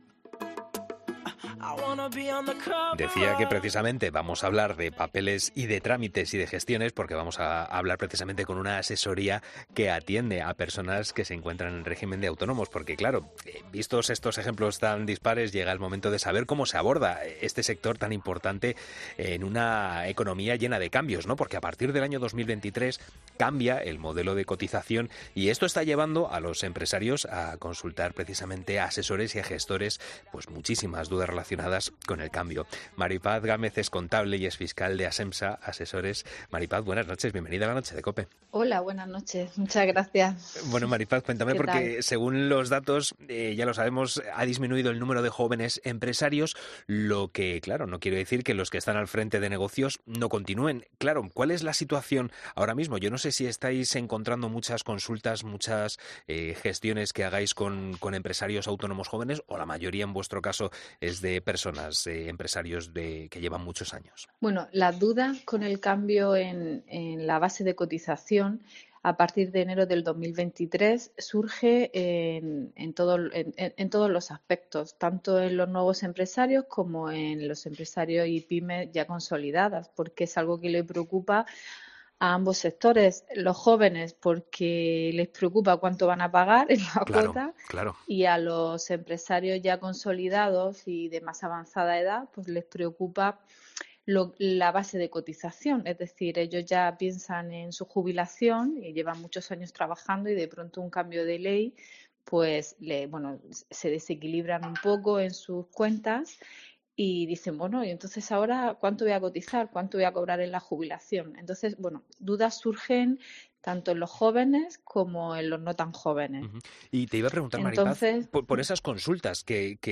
Una contable explica en 'La Noche' de COPE los detalles del nuevo modelo y aconseja a los autónomos que hacer cuando entre en vigor